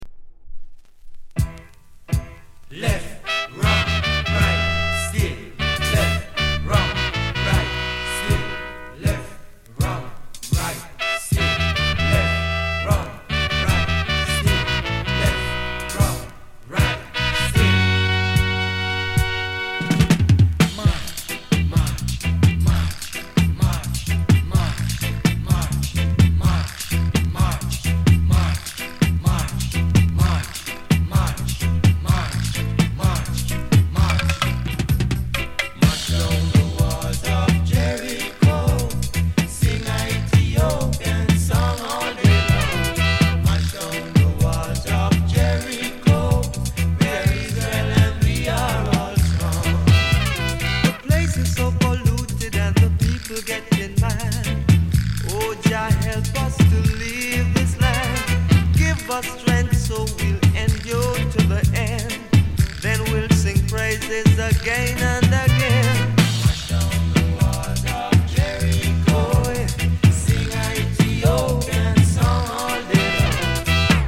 US 高音質